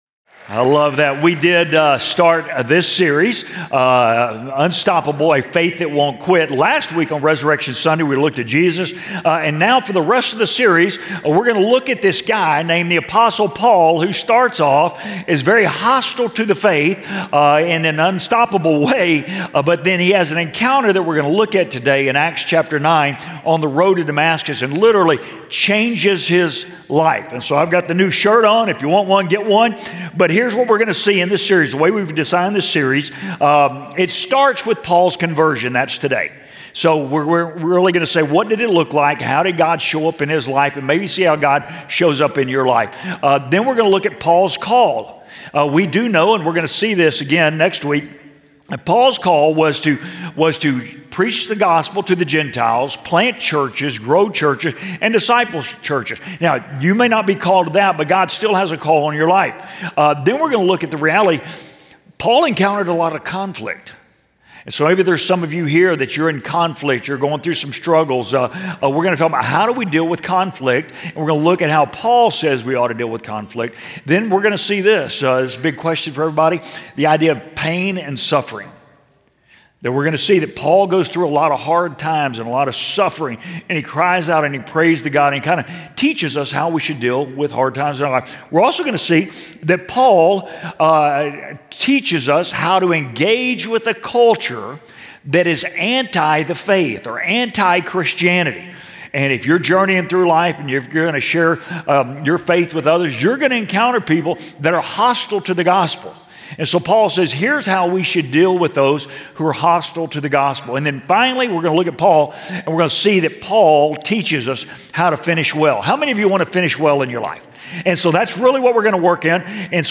finalsermon41226.mp3